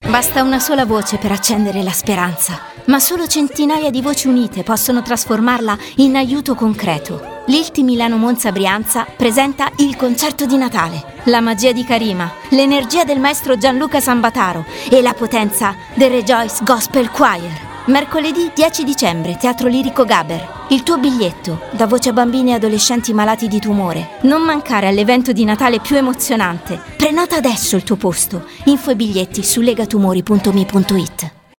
Spot Radiofonico
lilt-spot-radiofonico-definitivo.m4a